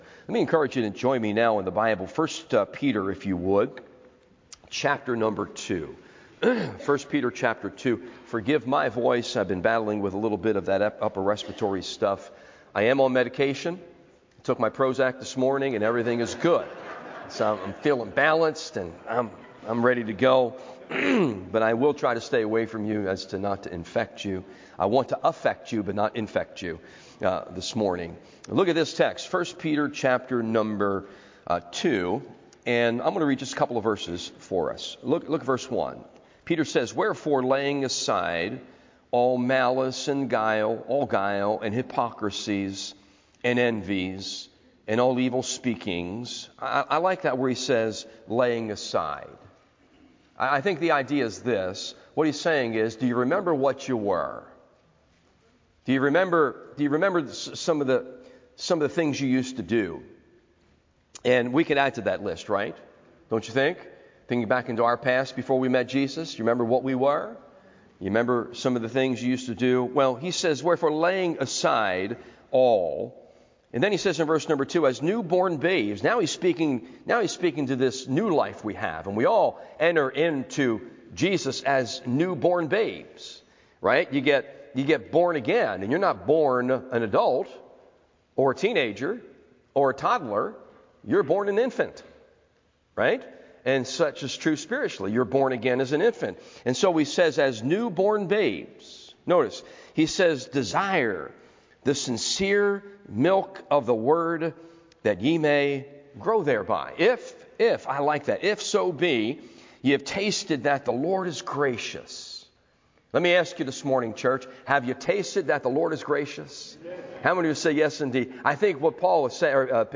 Always Abounding Series Sunday AM Service